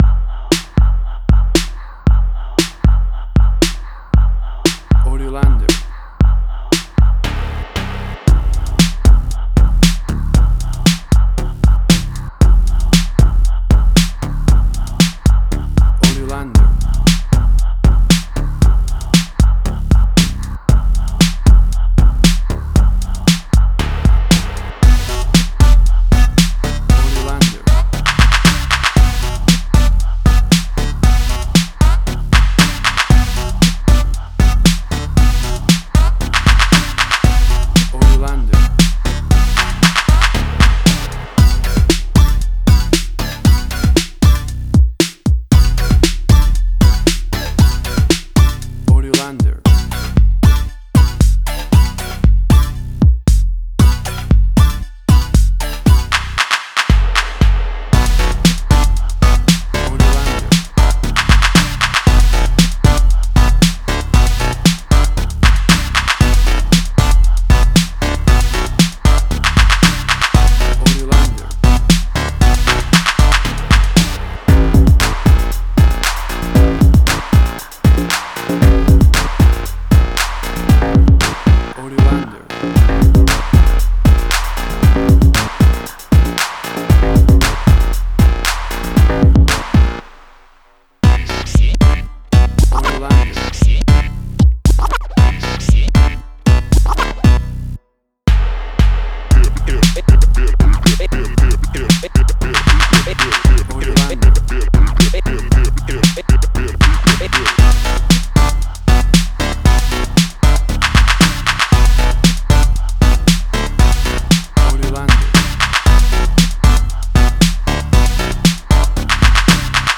emotional music
Tempo (BPM): 116